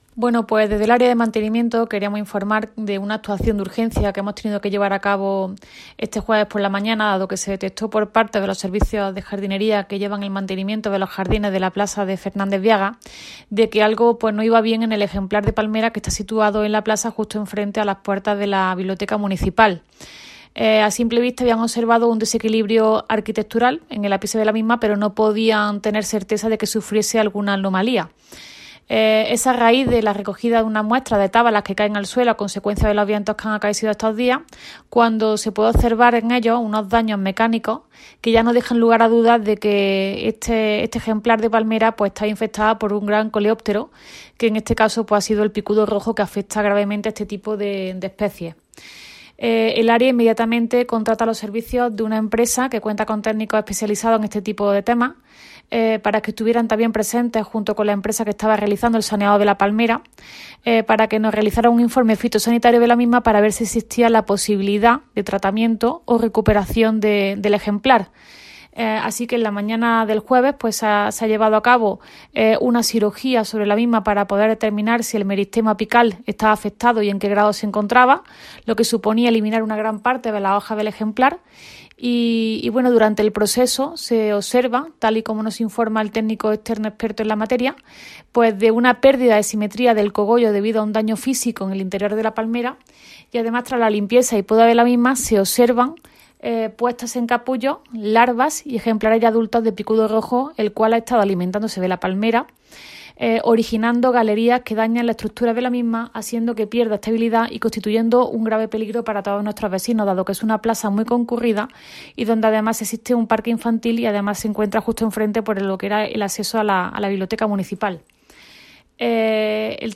La teniente de alcalde delegada de Mantenimiento, Teresa Molina, confirma el desarrollo de una actuación de urgencia en la plaza de Fernández Viagas ante la situación en que había quedado una palmera frente a la Biblioteca de San Zoilo.
Cortes de voz